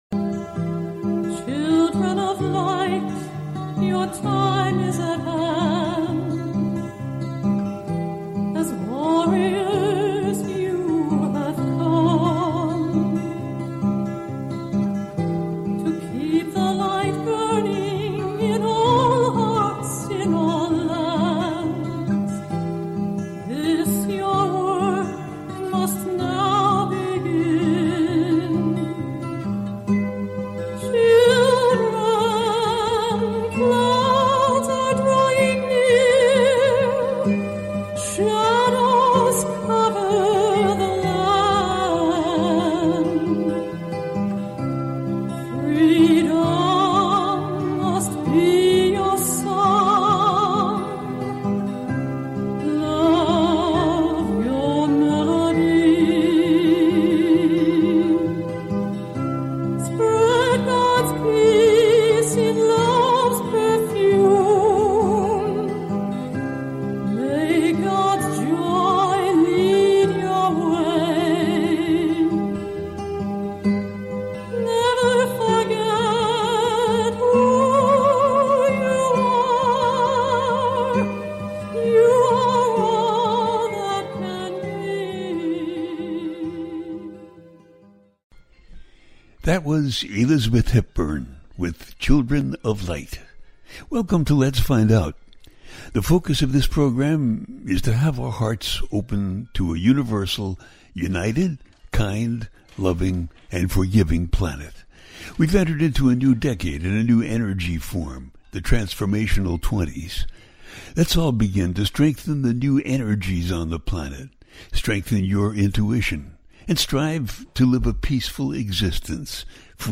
The Upliftment Of 2025 Part 2 - A teaching show